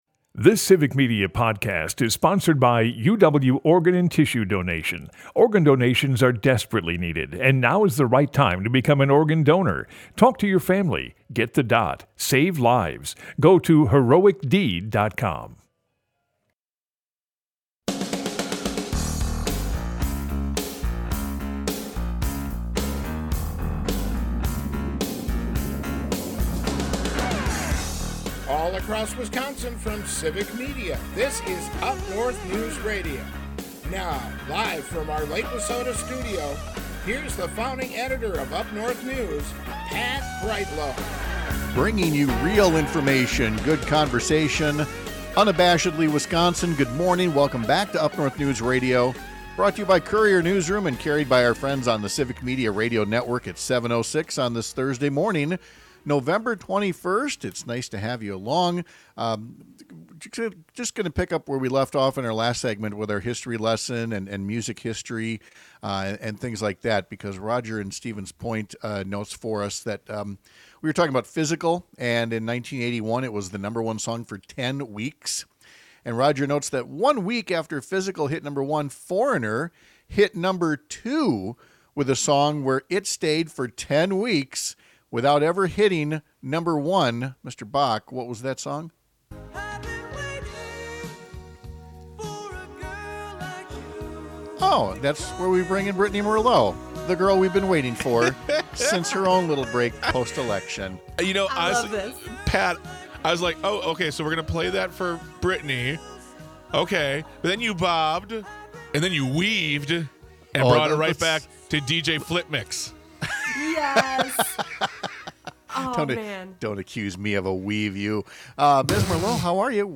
UpNorthNews is Northern Wisconsin's home for informative stories and fact-based conversations. Broadcasts live 6 - 8 a.m. across the state!